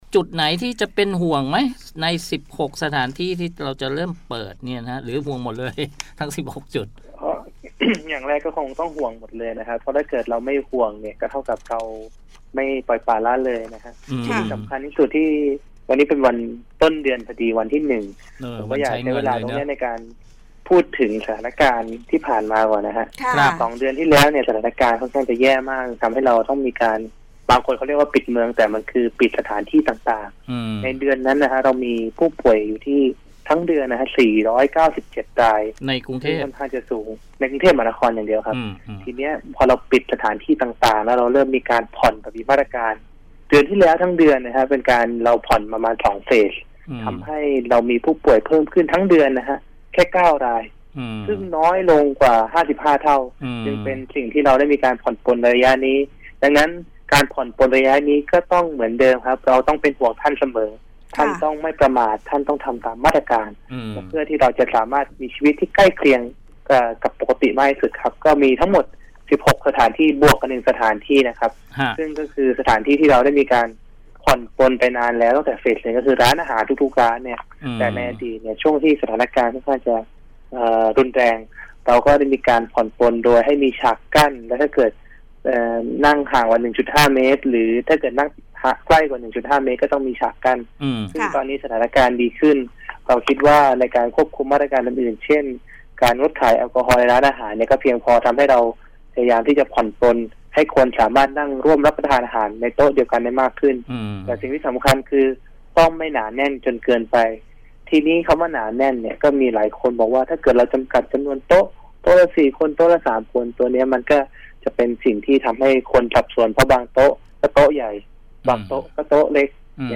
สัมภาษณ